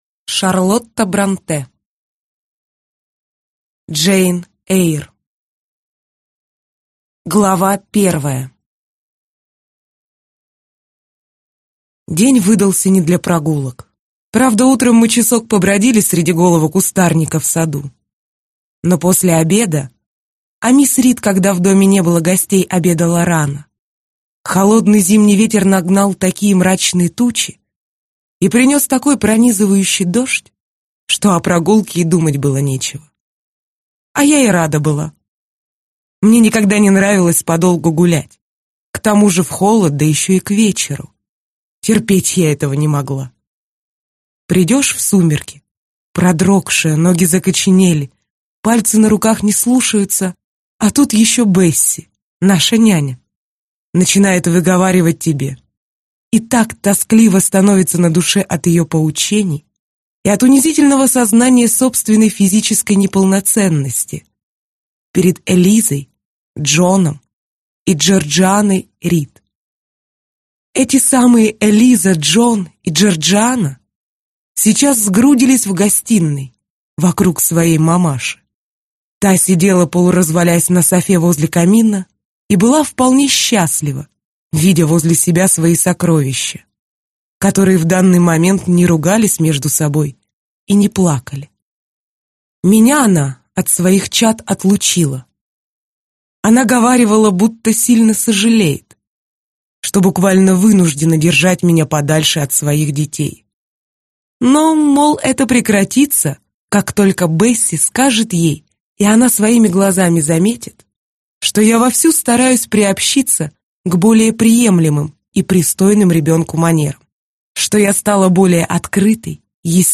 Аудиокнига Джейн Эйр | Библиотека аудиокниг